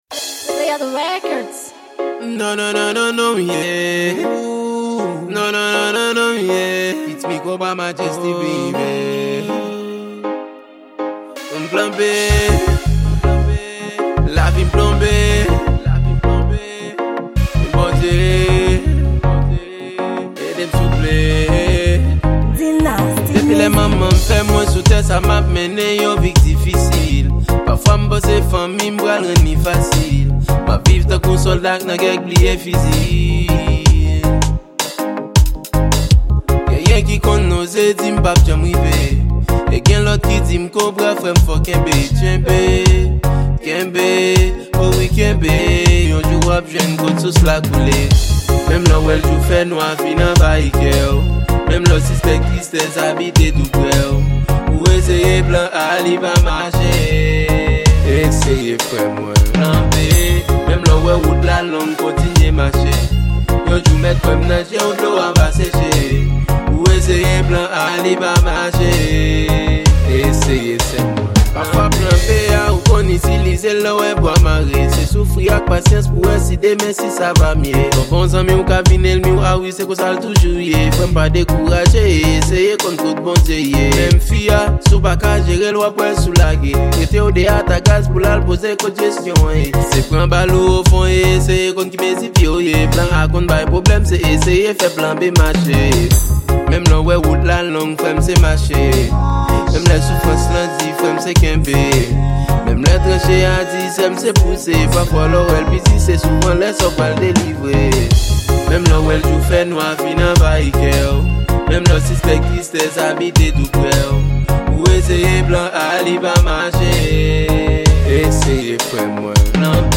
Genre : Reggae